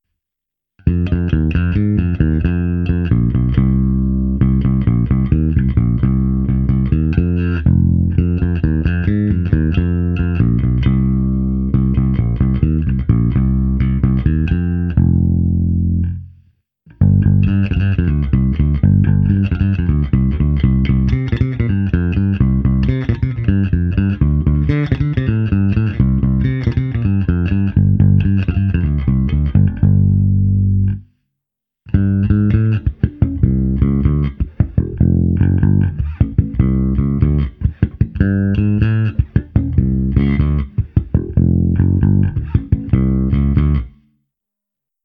Pro představu jak hraje basa přes aparát jsem ji prohnal softwarovým simulátorem AmpliTube 4.
Ukázka "mého" zvuku přes AmpliTube